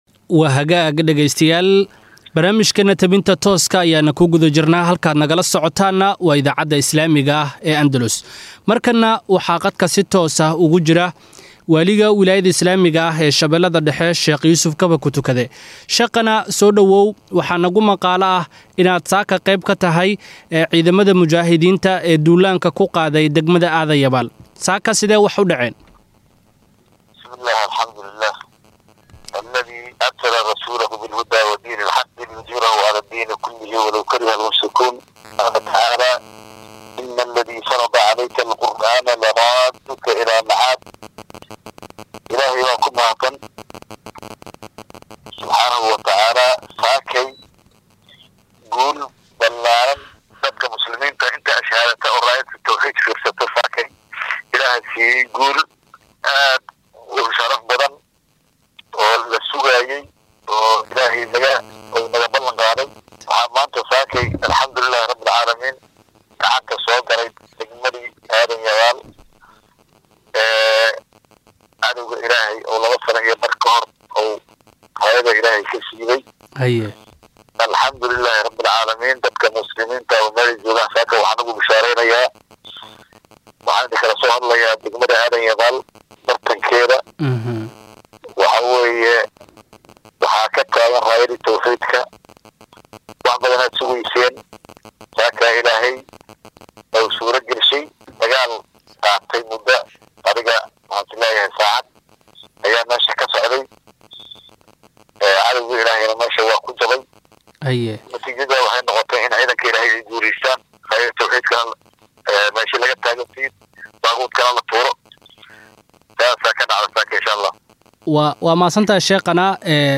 waraysi gaar ah qadka Taleefanka